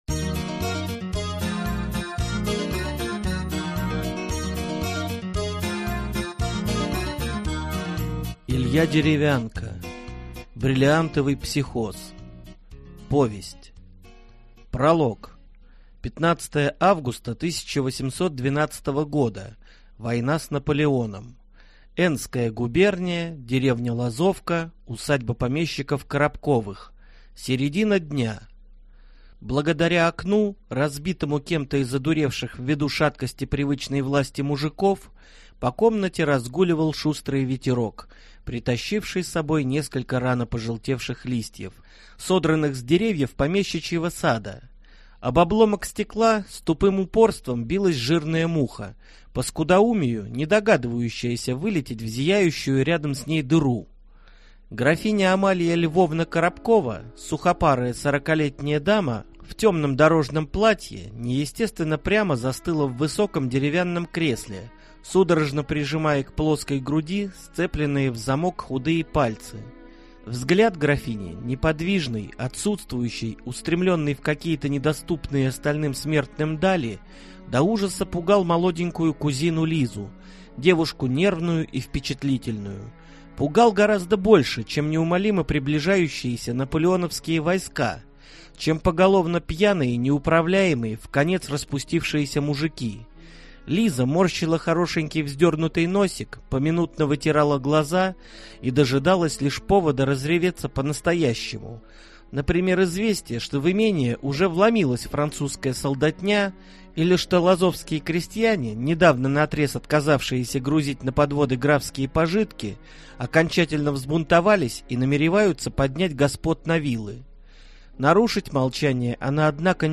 Аудиокнига Бриллиантовый психоз | Библиотека аудиокниг